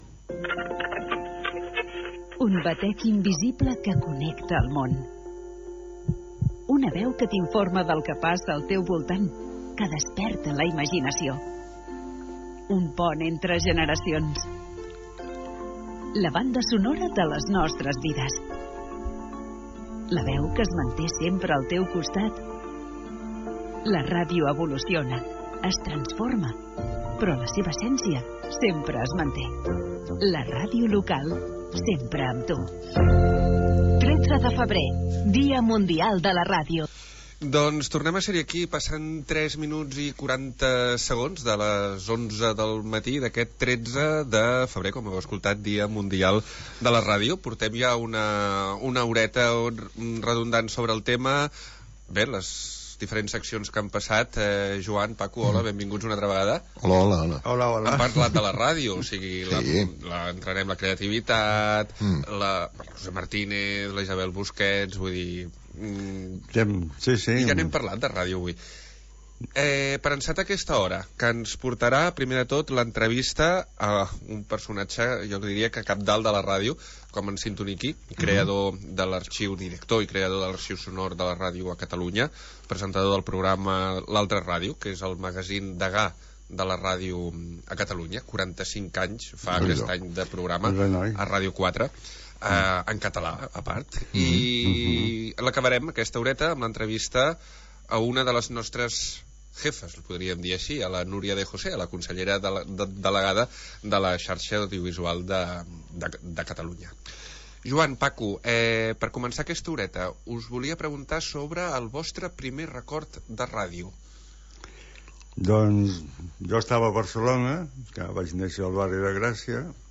Indicatiu de la ràdio i tema musical.